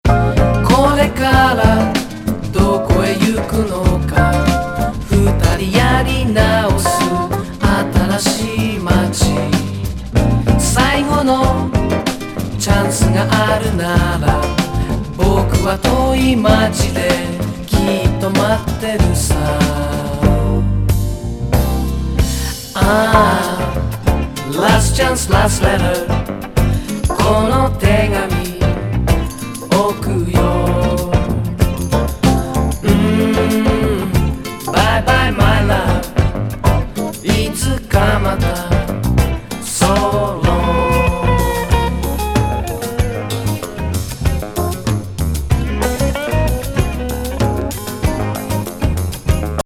風街スムース・ファンク・